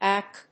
エー‐ビー‐シーシー